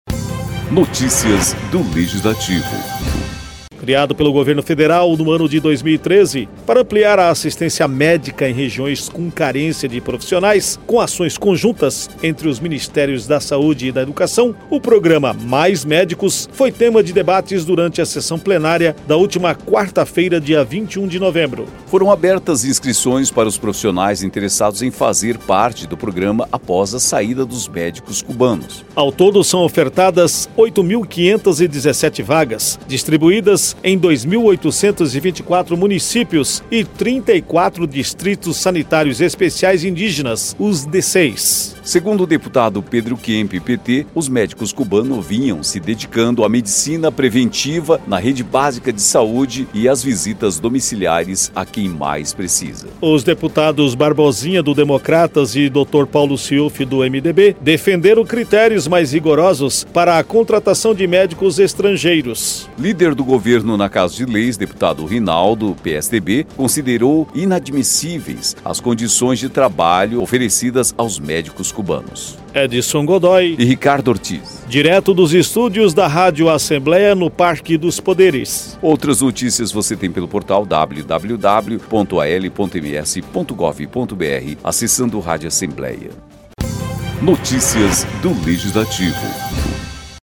Criado pelo Governo Federal em 2013, para ampliar a assistência médica em regiões com carência de profissionais, com ações conjuntas entre os Ministérios da Saúde e da Educação, o Programa Mais Médicos foi tema de debates durante a sessão plenária desta quarta-feira (21).